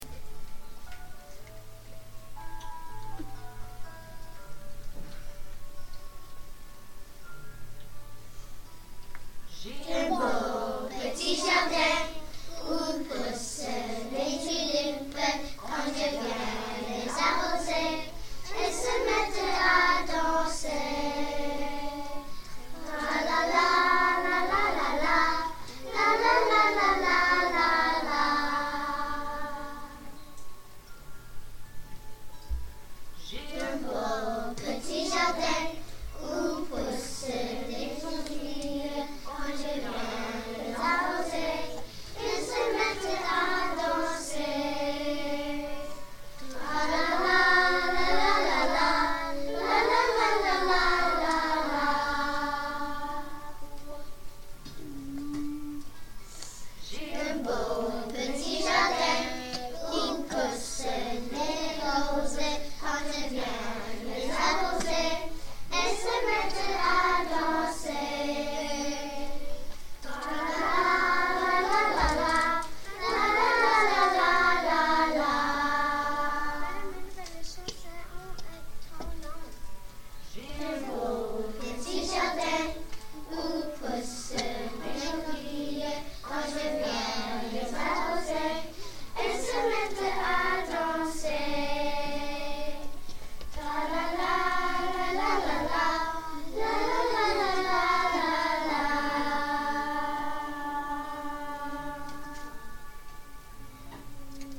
J'ai un beau petit jardin chanté par la classe 1C